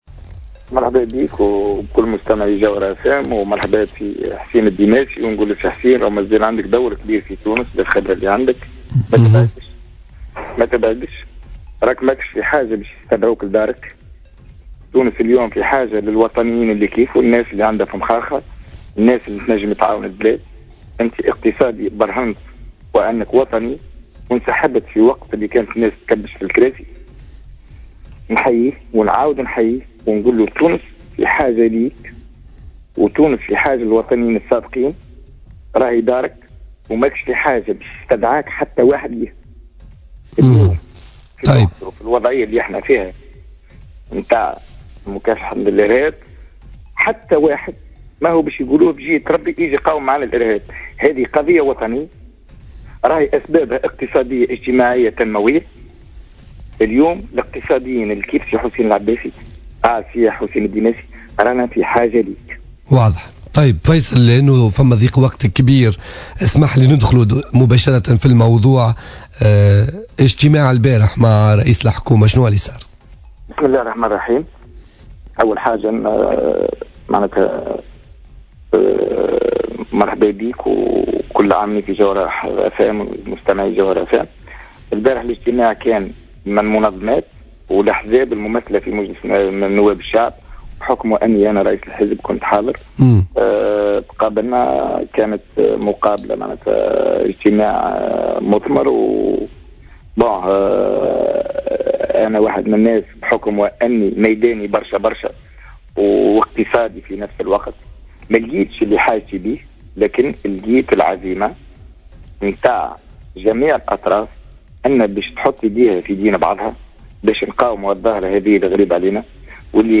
Intervenant sur les ondes de Jawhara FM dans le cadre de l’émission Politica, le député à l’assemblée des représentants du peuple, Faicel Tebbini a estimé que seule l’union de tous les tunisiens pourra combattre le terrorisme.